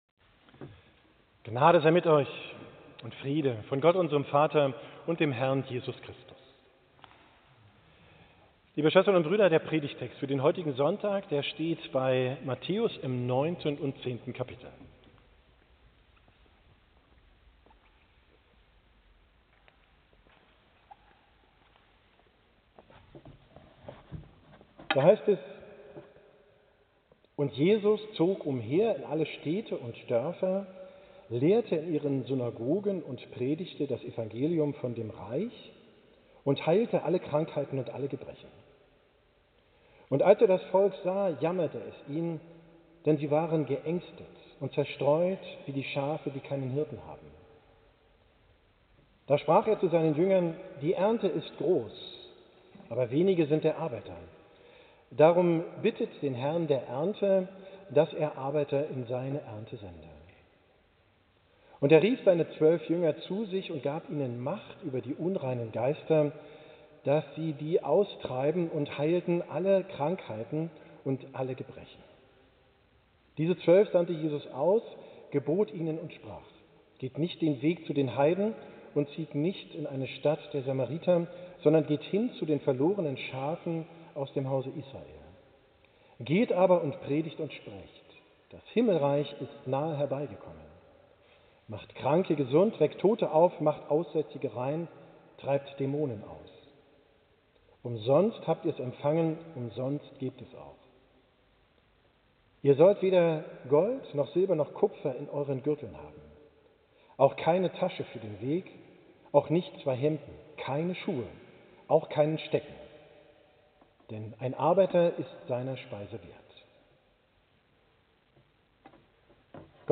Predigt vom 5. Sonntag nach Trinitatis, 20.